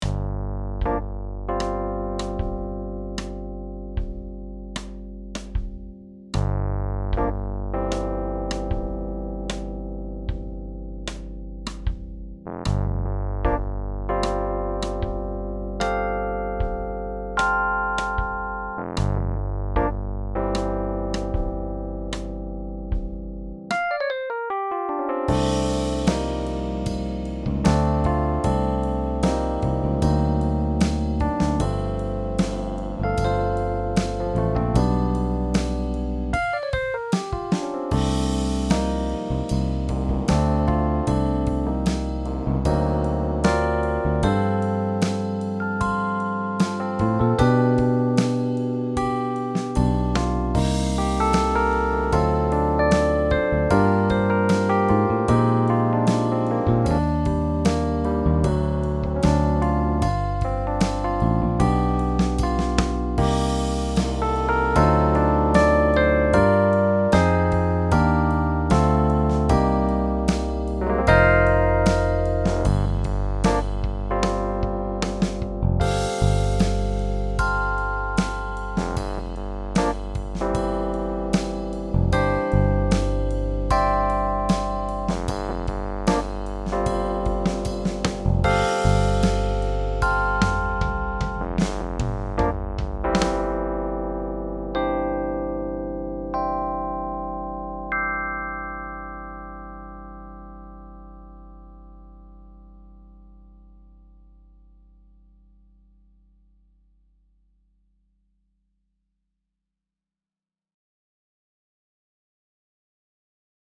Audio Demos